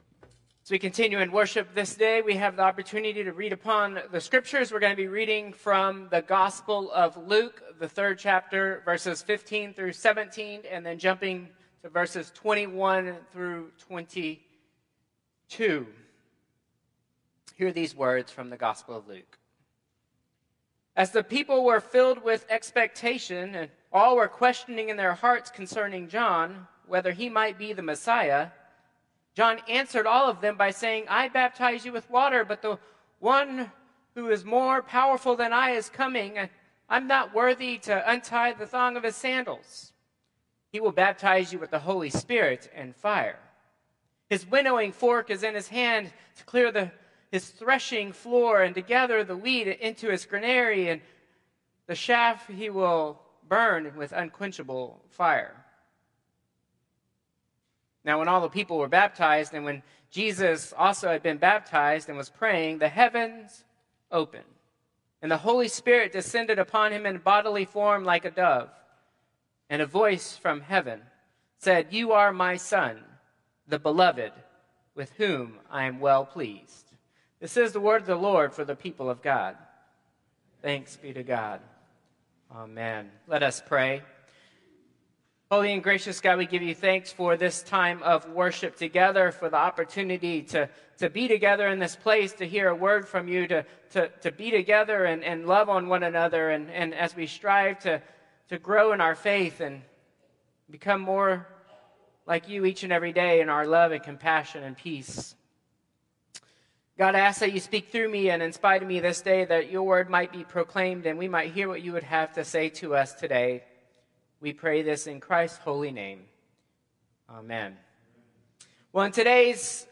Traditional Service 1/12/2025